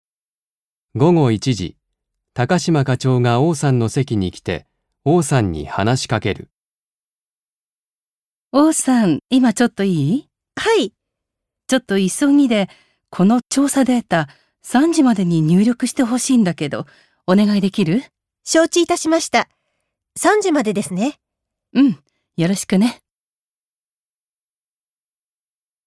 1. 会話